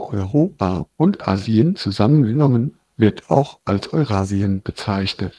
sample03-waveglow.wav